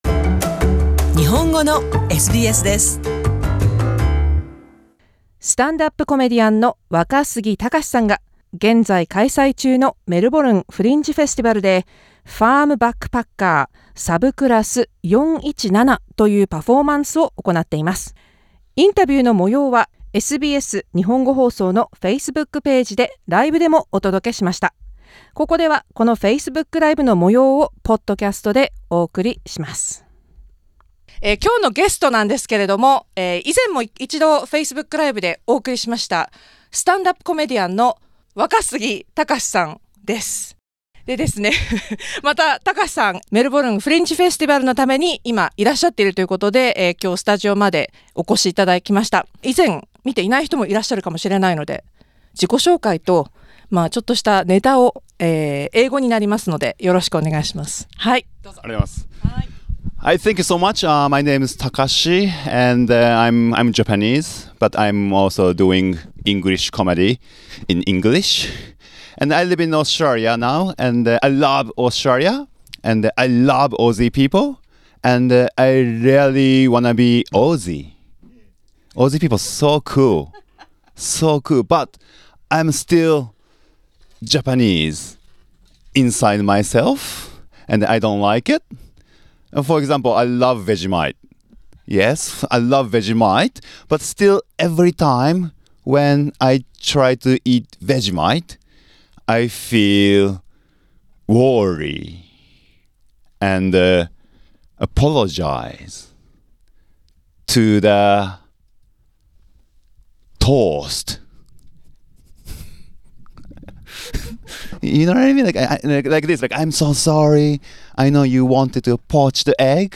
（2019年9月12日 フェイスブックライブで放送）